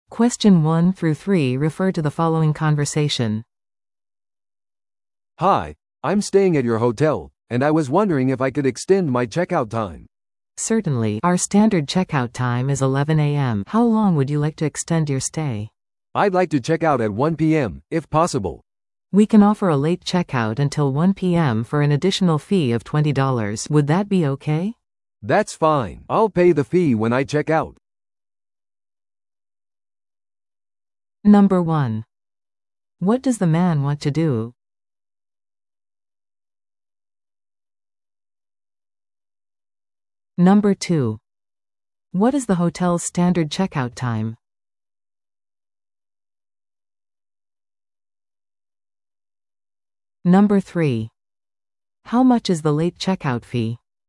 TOEICⓇ対策 Part 3｜ホテルのレイトチェックアウト依頼会話 – 音声付き No.56